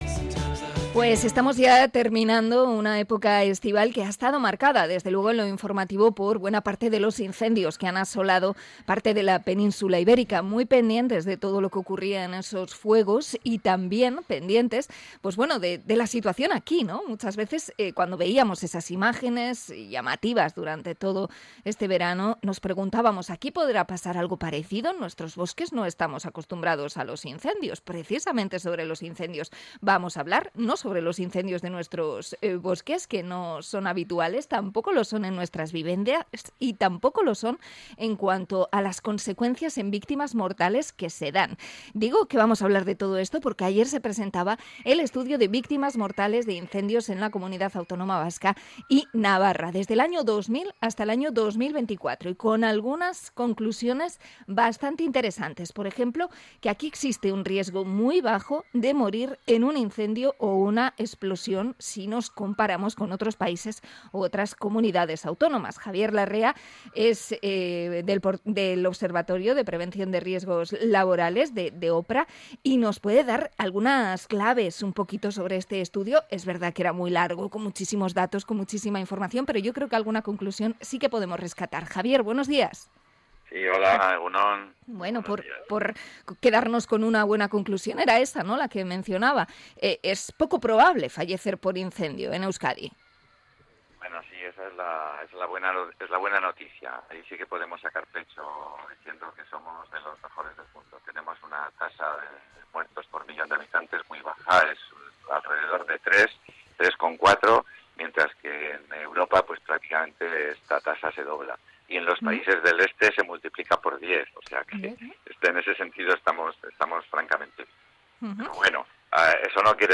Entrevista a experto en prevención de incendios